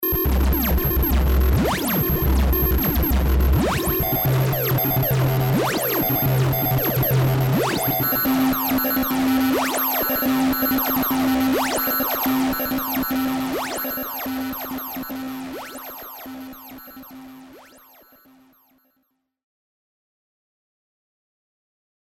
Stepperを使用してポリリズム的な音色変化を持った音色を使用したフレーズ例です。
リバーブやディレイは本体内蔵エフェクトのみ使用しています。
ただし、音色の設定自体が比較的歪んだサウンドですので、演奏のアプローチによってはラウドなだけになってしまう場合もありますので、色々試してみると良いでしょう。